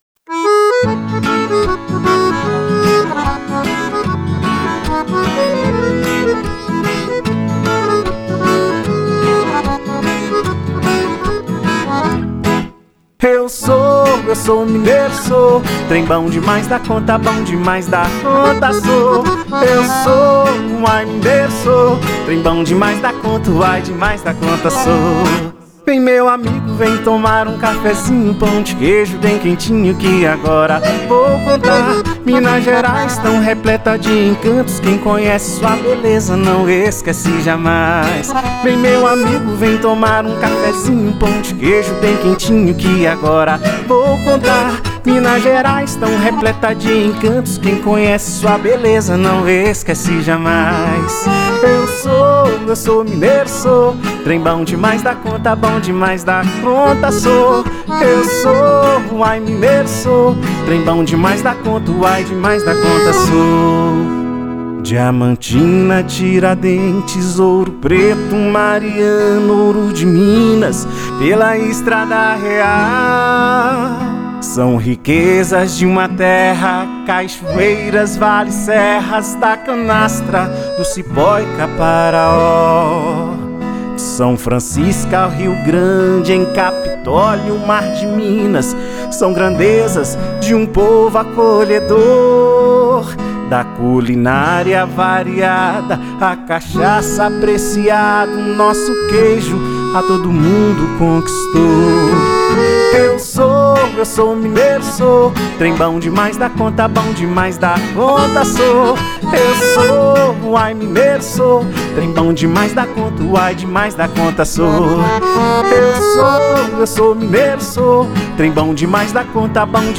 Eu-Sou-Mineiro-Sô-Voz.wav